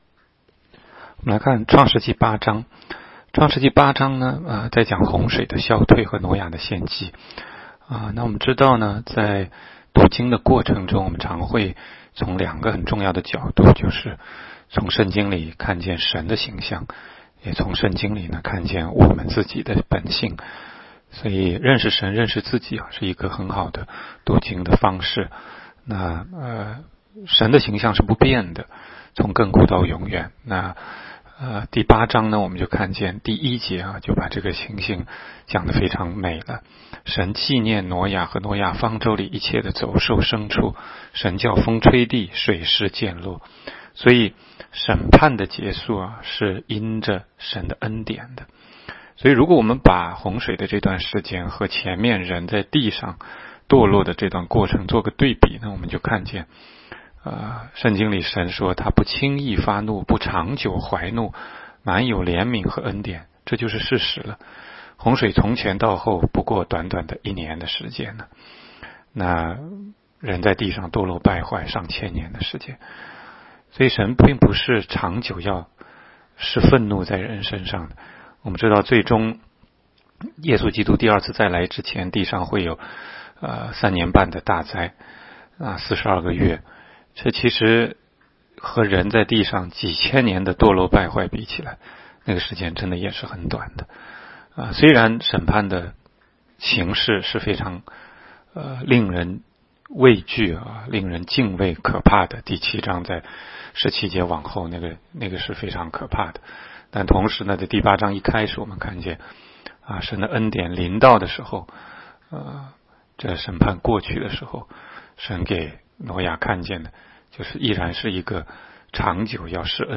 16街讲道录音 - 每日读经-《创世记》8章